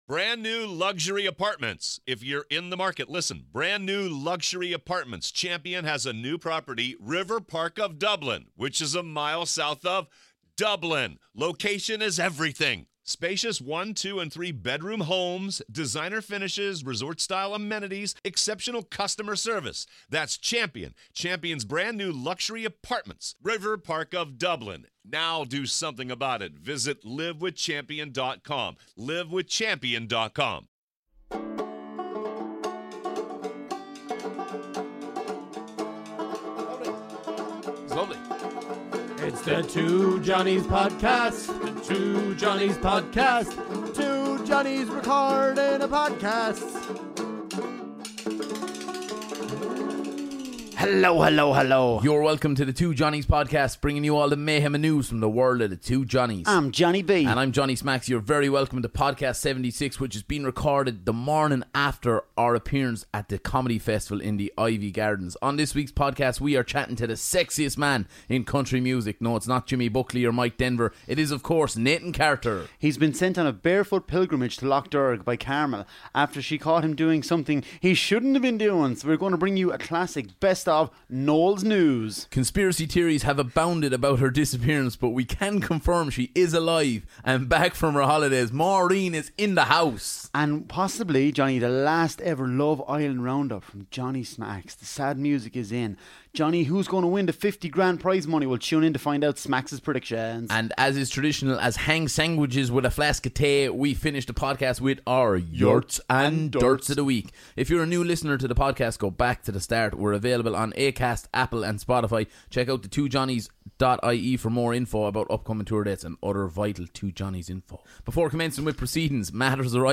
Ireland's favourite comedy duo tackle the big issues.